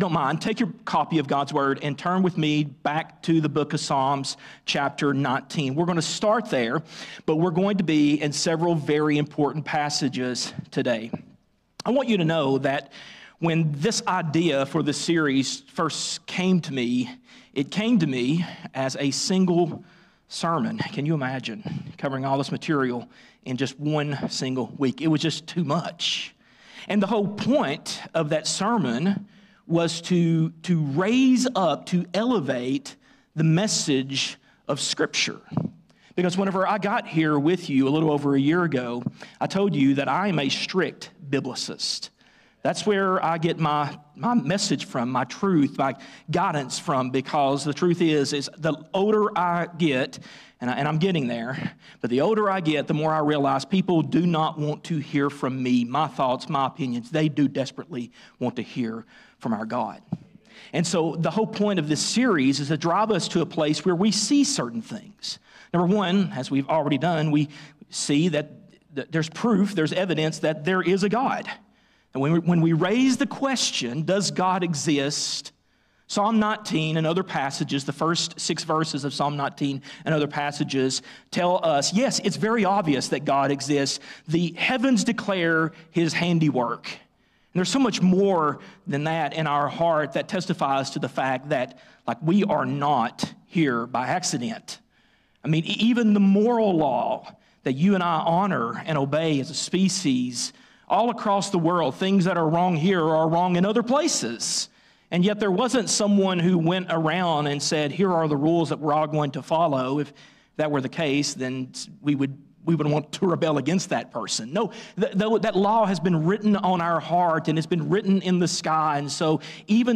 A message from the series "Faith For A Reason."